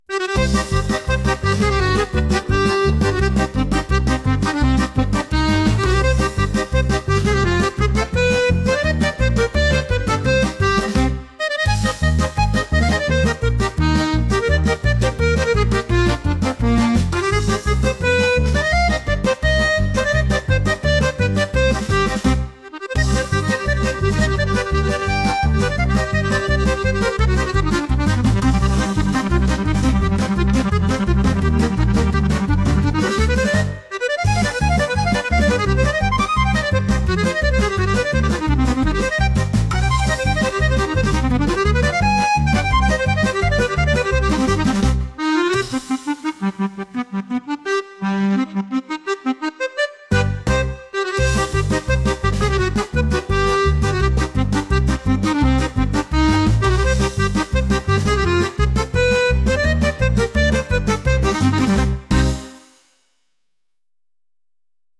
急いでいるようなハイテンポなアコーディオン曲です。